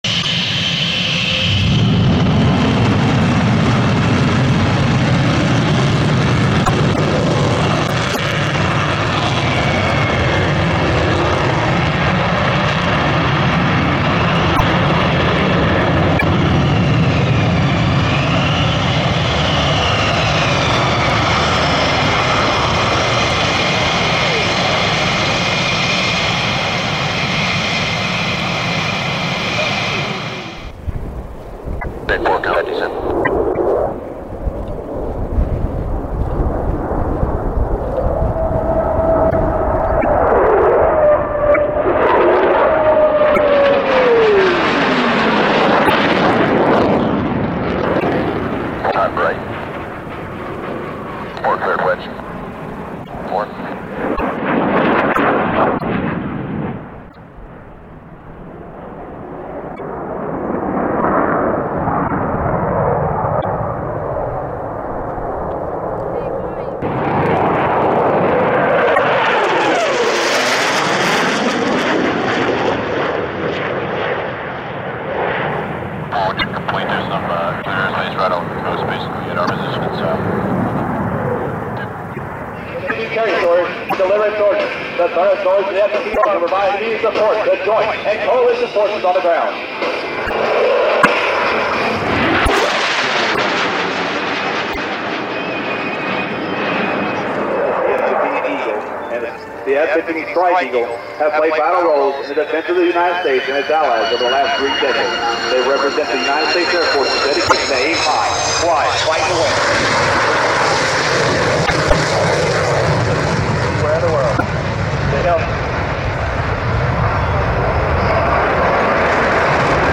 jet.mp3